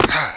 JUMP-1.WAV